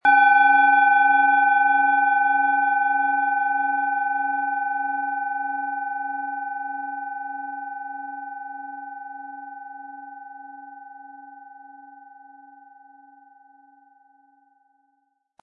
Planetenton
Von erfahrenen Meisterhänden in Handarbeit getriebene Klangschale.
Sie möchten den Original-Ton der Schale hören? Klicken Sie bitte auf den Sound-Player - Jetzt reinhören unter dem Artikelbild.
Spielen Sie die Pluto mit dem beigelegten Klöppel sanft an, sie wird es Ihnen mit wohltuenden Klängen danken.
MaterialBronze